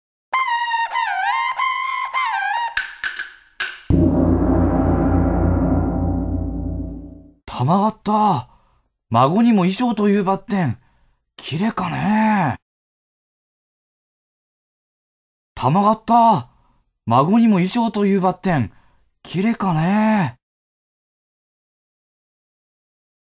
• 読み上げ